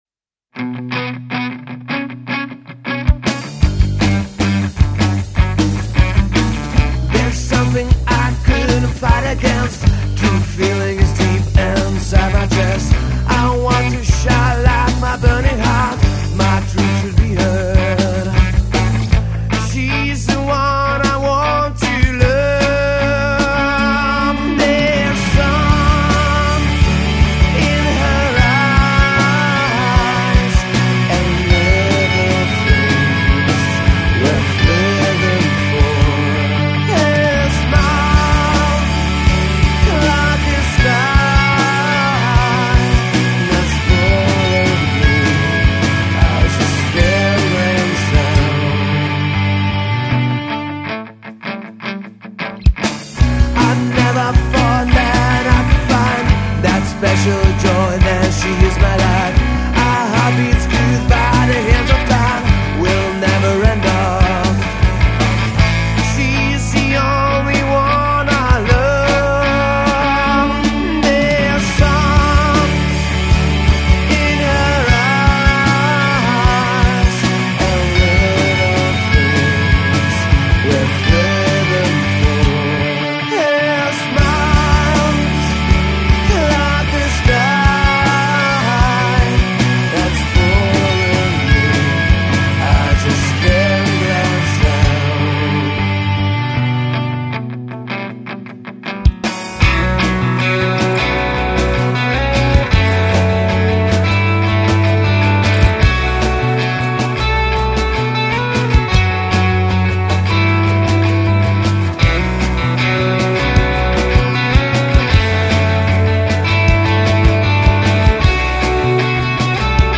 programavimas/piano